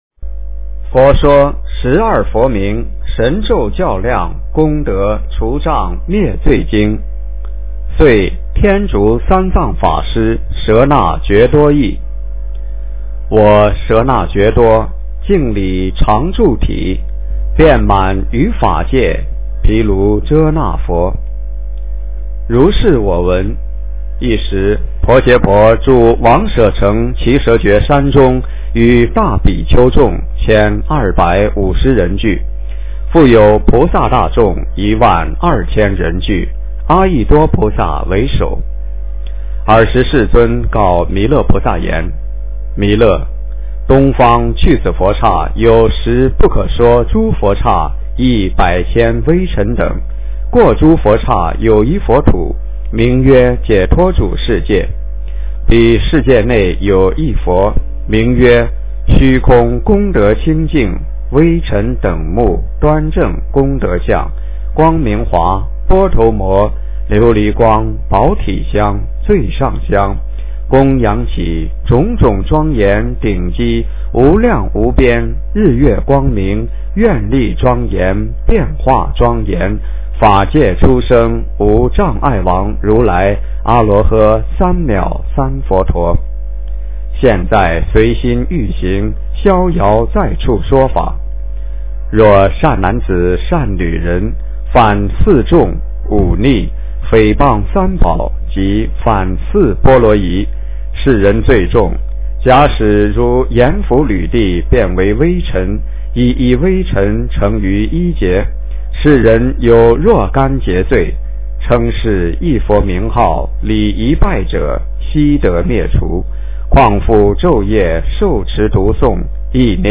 佛说十二佛名神咒校量功德除障灭罪经 - 诵经 - 云佛论坛